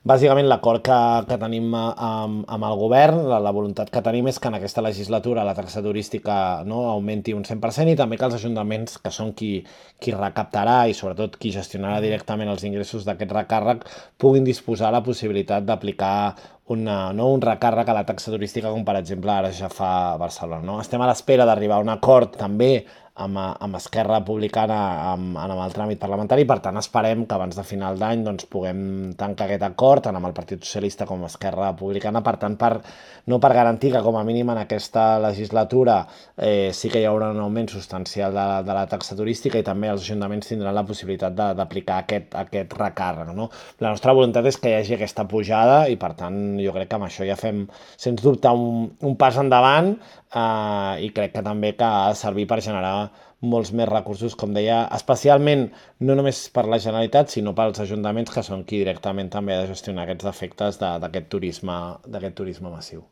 El diputat dels Comuns explica que una de les voluntats d’aquesta legislatura és que es faci efectiva l’augment de la taxa turística. A més, Cid també avança que els Comuns buscaran arribar a un acord amb Esquerra Republicana en l’àmbit parlamentari per “garantir un augment substancial de la taxa”.